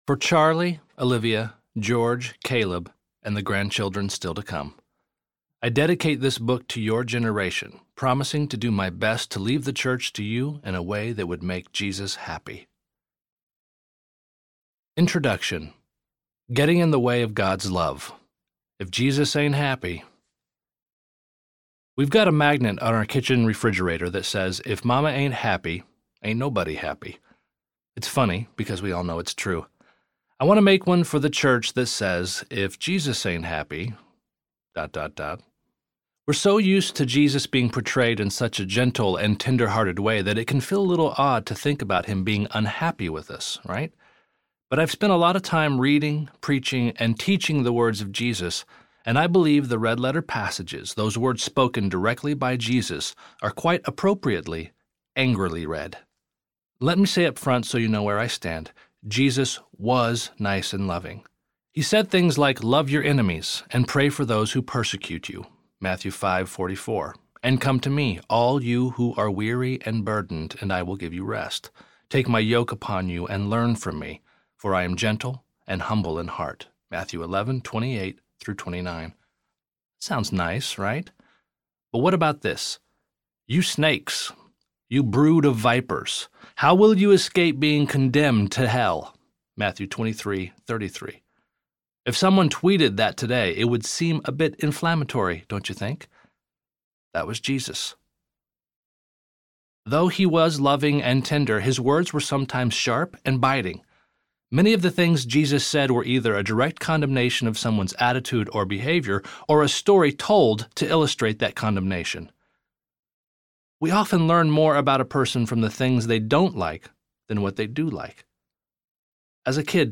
What Made Jesus Mad? Audiobook
Narrator
5.68 Hrs. – Unabridged